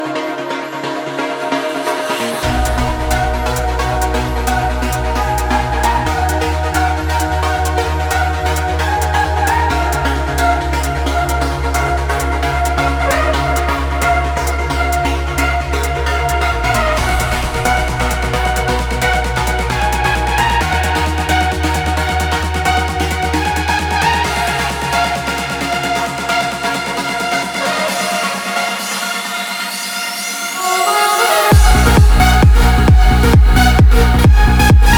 Dance Electronic
Жанр: Танцевальные / Электроника